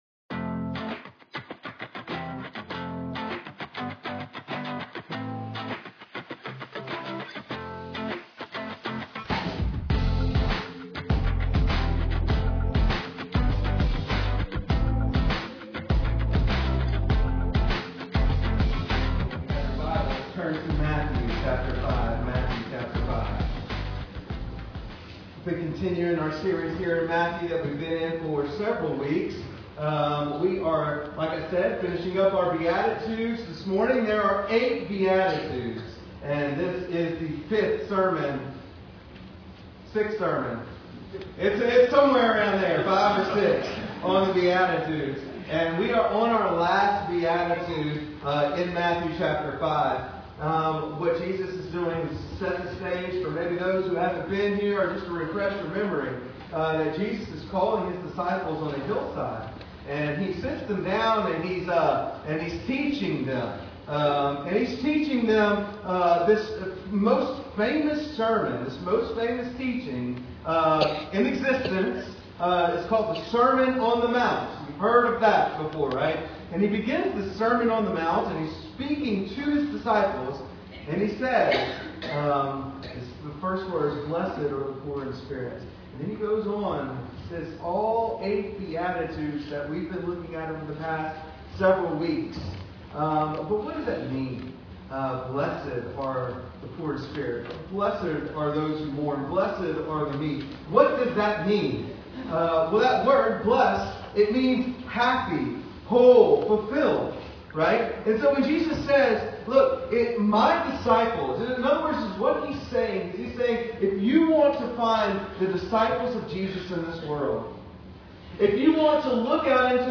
Sermons | Trace Creek Baptist Church